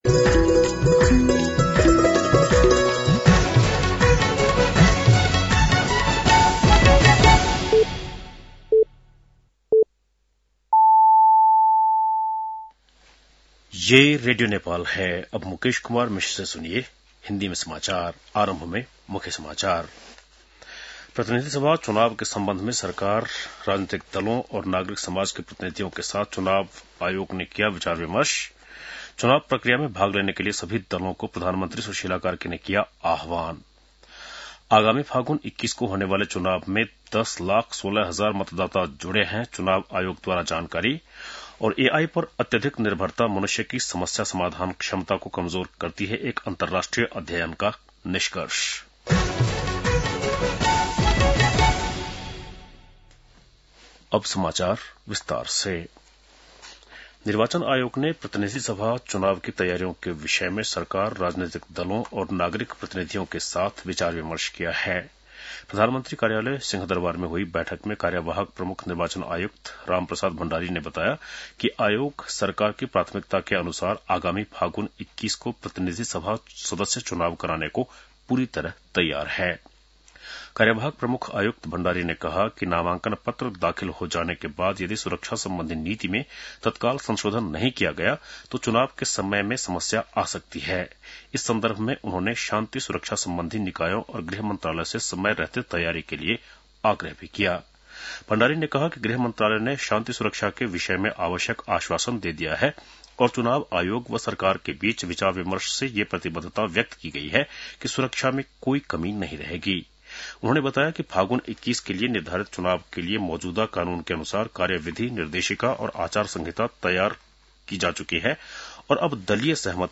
An online outlet of Nepal's national radio broadcaster
बेलुकी १० बजेको हिन्दी समाचार : ७ पुष , २०८२
10-PM-Hindi-NEWS-9-07.mp3